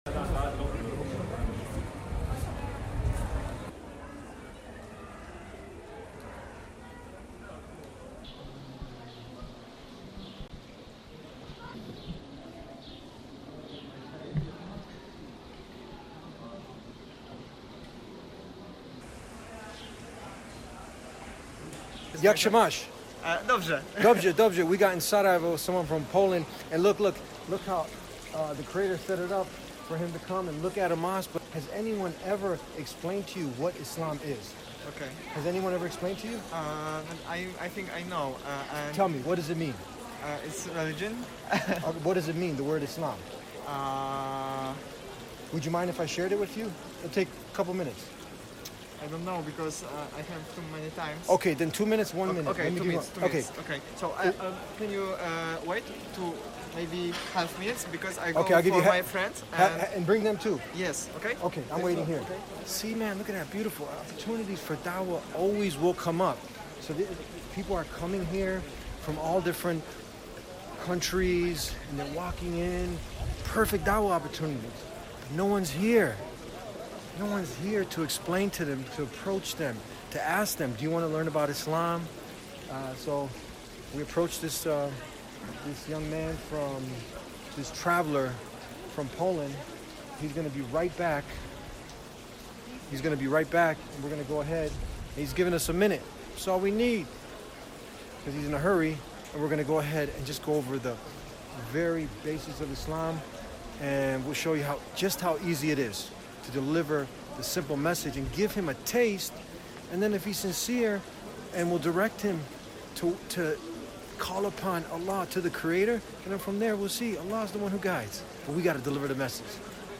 POLISH MEN visit Masjid learn about Islam – In Sarajevo – Bosnia and Herzegovina